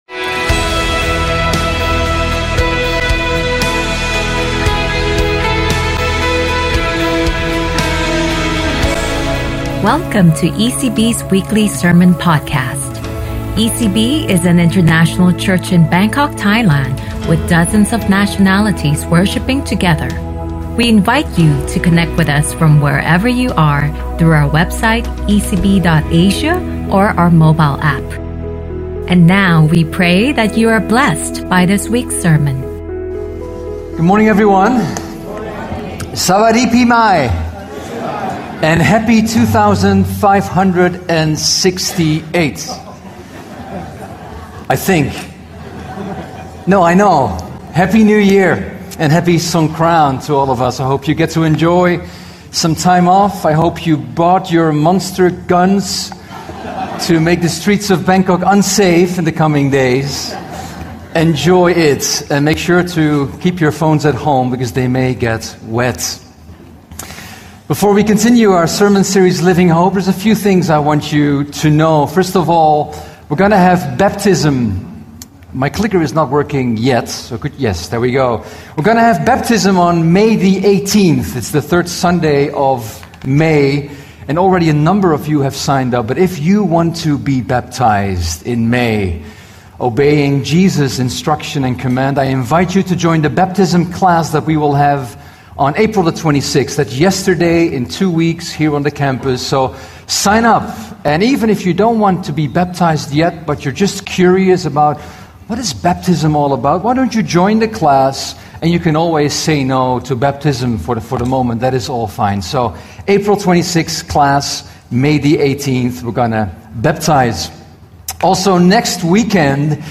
ECB is an international church in Bangkok, Thailand with dozens of nationalities worshiping together weekly.We welcome you to join us from wherever you are with this live audio recording of our Sunday sermons.